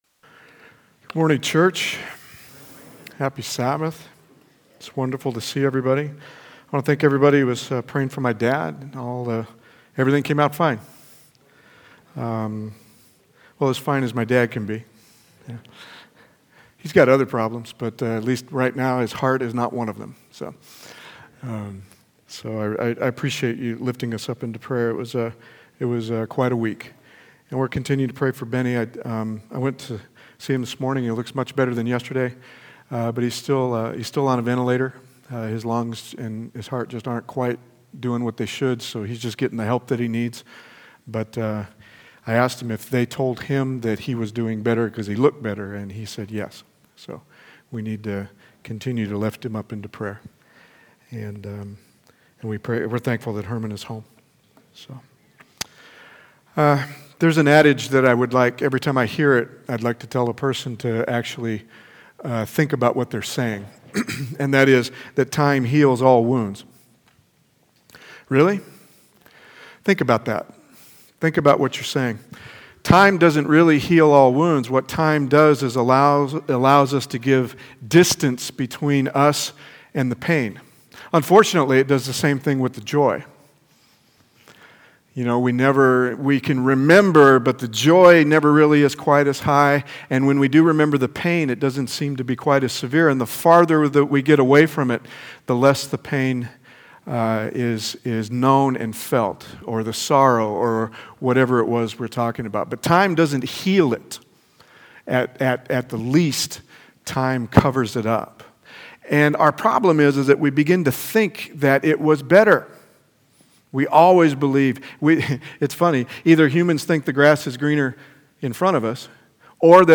Past Sermons